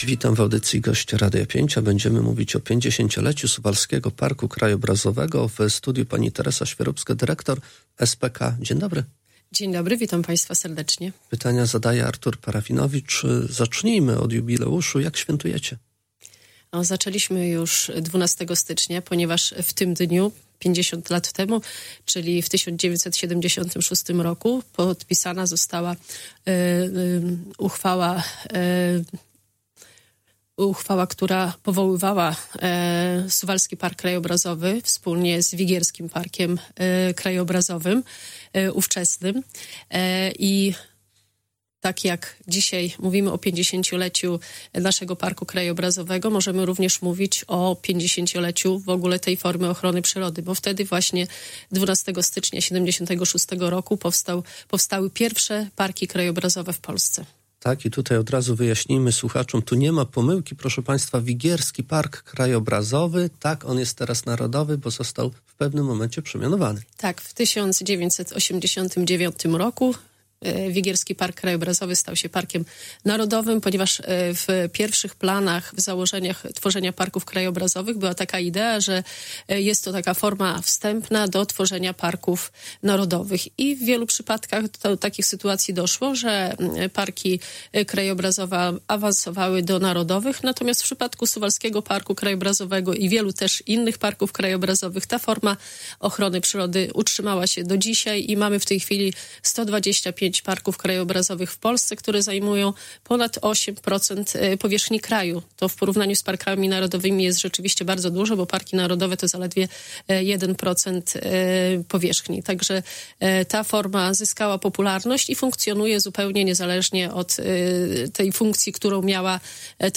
Cała rozmowa: https